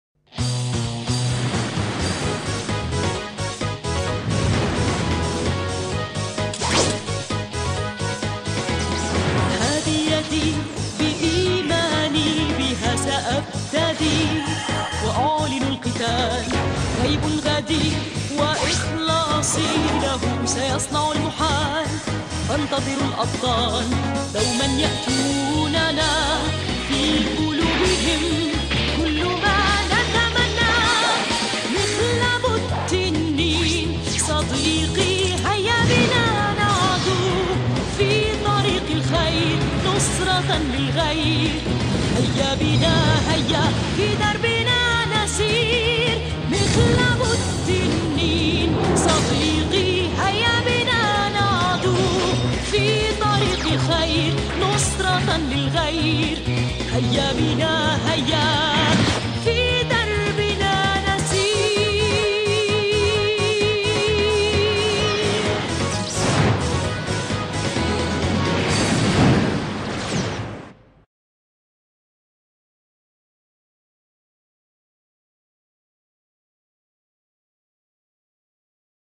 شارة البداية